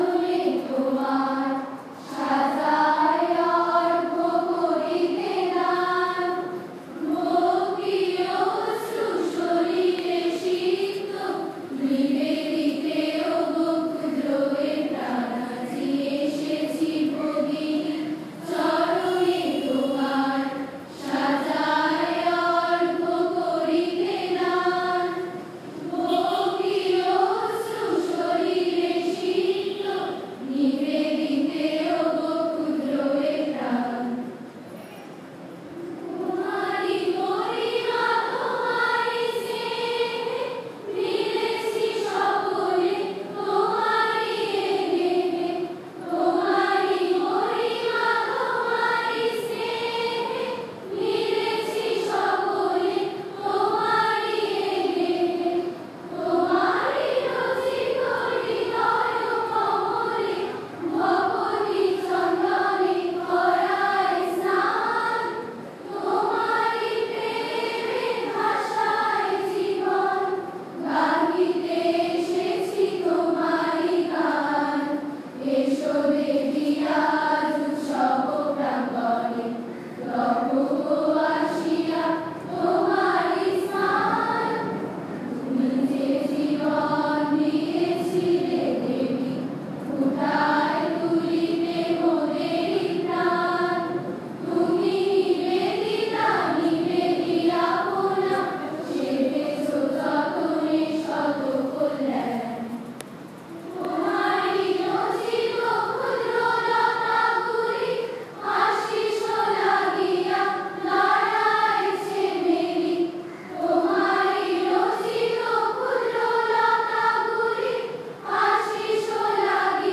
There was no harmonium or any musical instrument to support them, but they sang in melodious voices “Madhuvata Rutayate,”  “Sahana Vavatu…,” a hymn on Sri Sarada Devi, and a Bengali song on Sister Nivedita.
The following are small clips which give some idea of their singing:
sister-nivedita-school-girls-song2.m4a